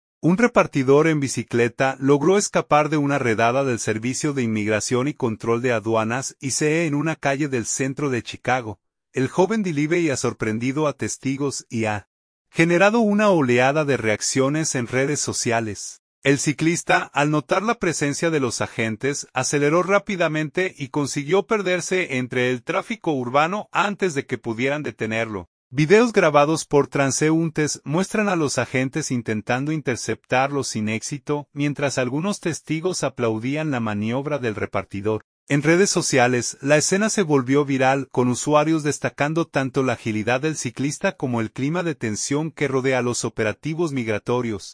Videos grabados por transeúntes muestran a los agentes intentando interceptarlo sin éxito, mientras algunos testigos aplaudían la maniobra del repartidor.